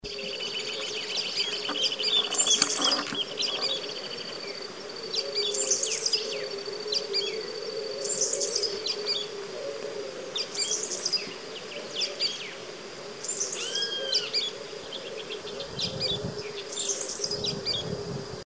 Espiguero Pardo (Asemospiza obscura)
Nombre en inglés: Dull-colored Grassquit
Localización detallada: Costa del Paraná en Villa Urquiza
Condición: Silvestre
Certeza: Observada, Vocalización Grabada